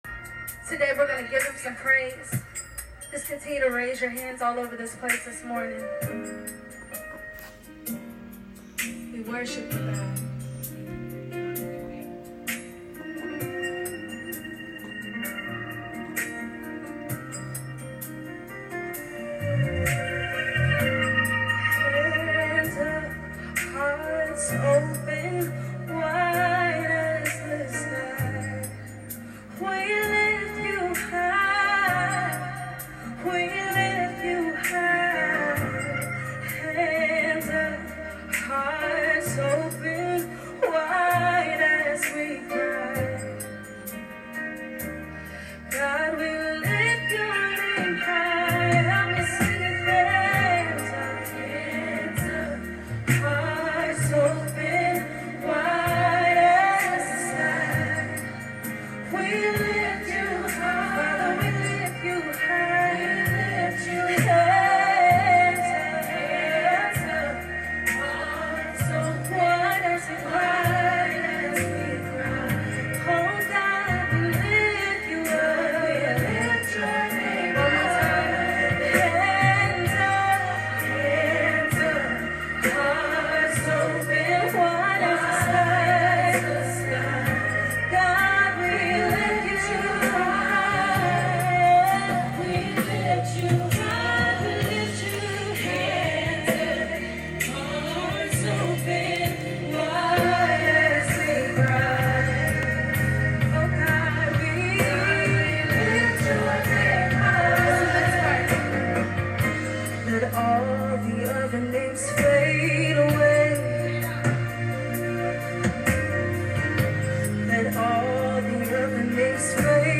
Worship Music:
”Wide As The Sky” –  Celebration Church Women’s Choir, Columbia, MD -(NAWCG Praise Raise-A-Thon), pre-recorded*
*NAWCG Praise Raise-A-Thon – Celebration Church Women’s Choir, Columbia, MD* – (pre-recorded)